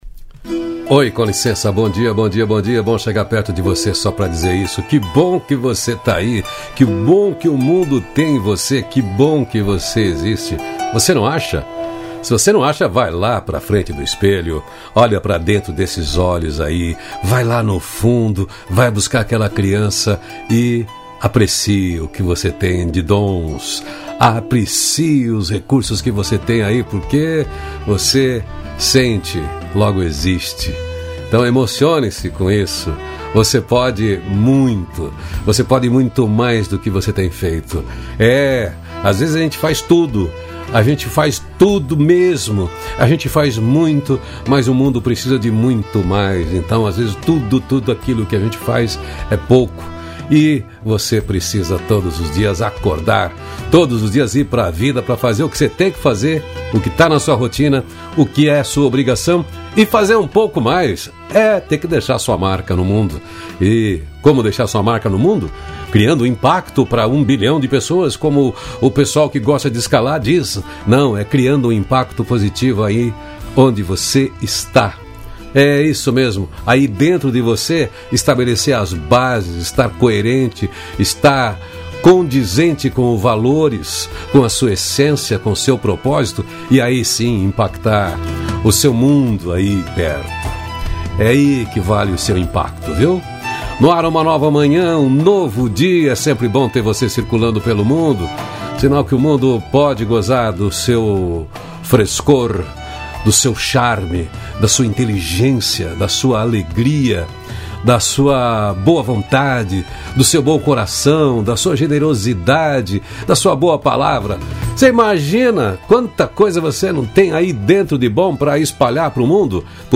Trilha sonora: Loreena Mckennitt “Between the shadows”.